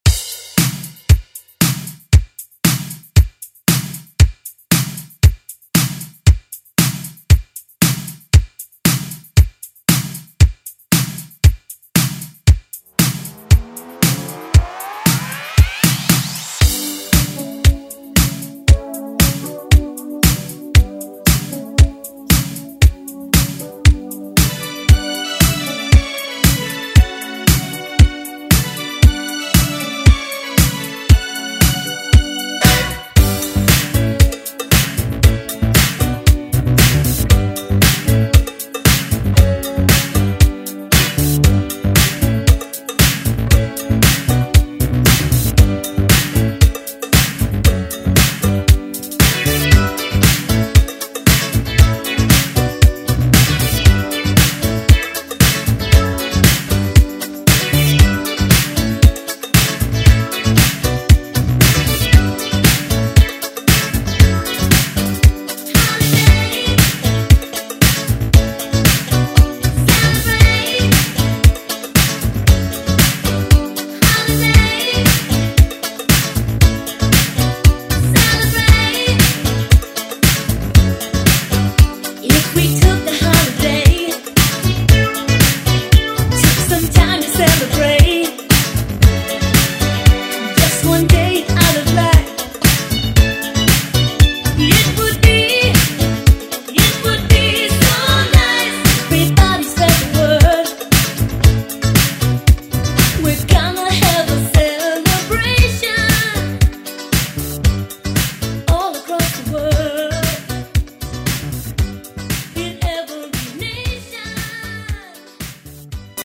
Genres: HIPHOP , R & B , RE-DRUM
Clean BPM: 96 Time